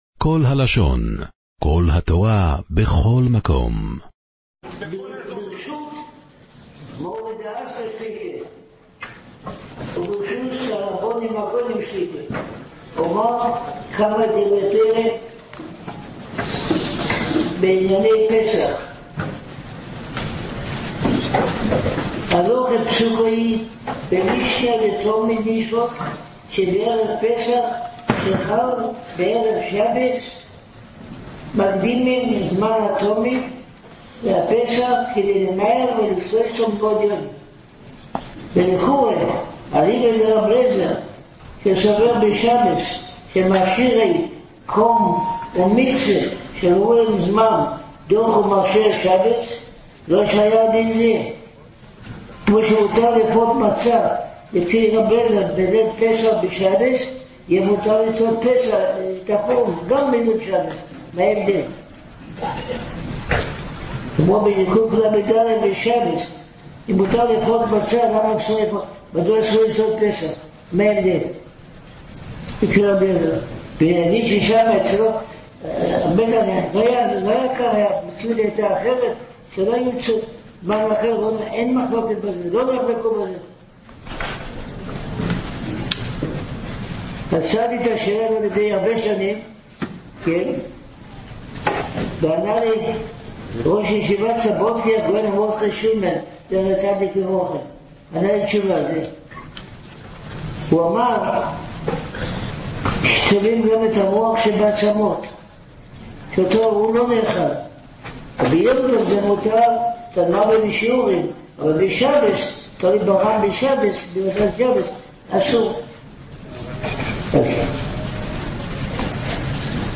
בקשה | תמלול שיעורי תורה עבור AI